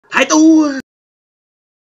เสียงโอ้ยกอนนพี่บูม เสียงแจ้งเตือน iG (Instagram)
คำอธิบาย: นี่คือคลิปเสียงมีมที่ "หายตัว" ของสตรีมเมอร์ชื่อดังชาวไทย OPZ TV มันได้ยินกี่ครั้ง ก็ฮา🤣 หากคุณเป็นแฟนตัวยงของสตรีมเมอร์ พี่บูม OPZ TV คุณสามารถดาวน์โหลดไฟล์เสียง พี่บูม หายตัว เพื่อใช้ในการพากย์วิดีโอตลกๆ เมื่อตัดต่อวิดีโอหรือตั้งเป็นเสียงเรียกเข้าโทรศัพท์ของคุณได้ค่ะ